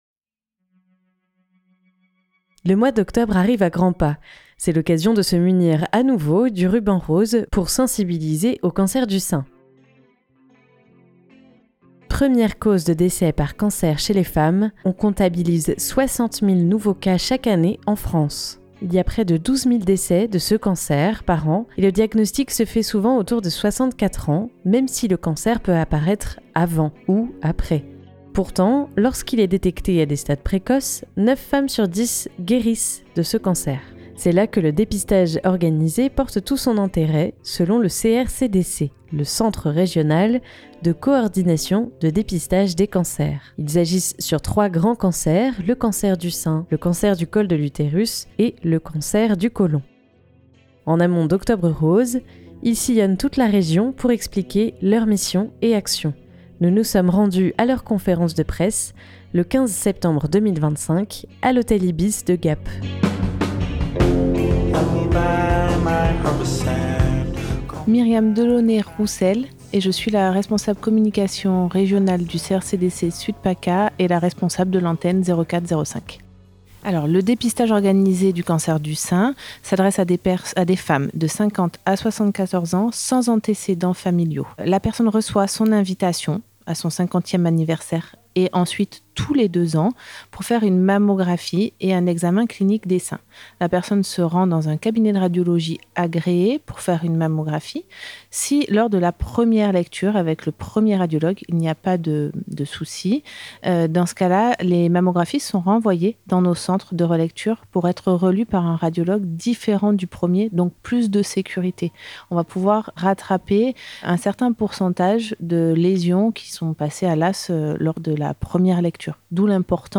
C'est là que le dépistage organisé porte tout son intérêt selon le CRCDC . En amont d'Octobre Rose, ils sillonnent toute la région pour expliquer leurs missions et actions. Nous nous sommes rendus à leur conférence de presse, le 15 septembre 2025, à l'hôtel Ibis de Gap.